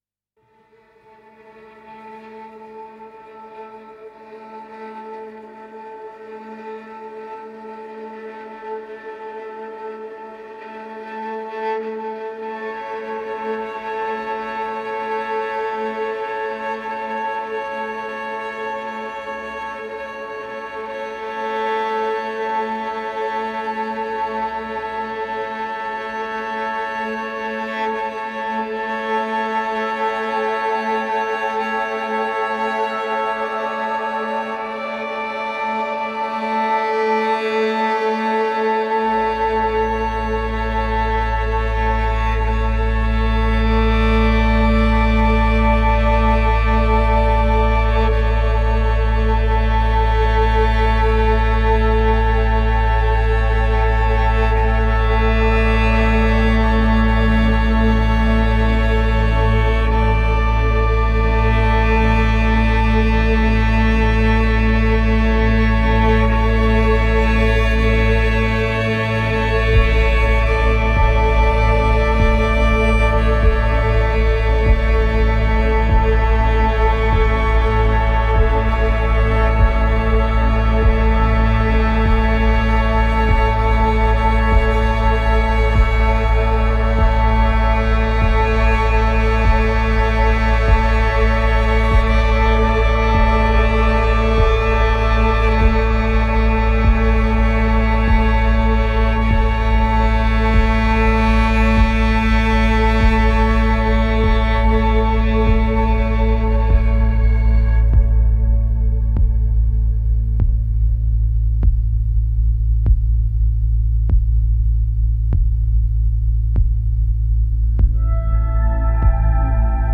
Lingering strings transition into a suspenseful intermezzo.